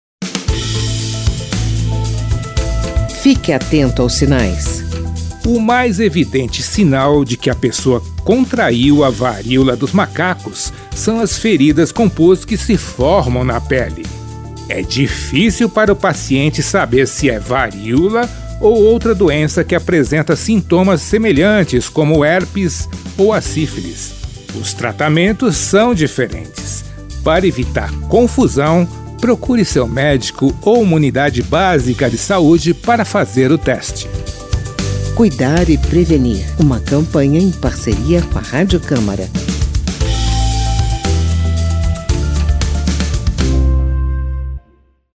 Texto e locução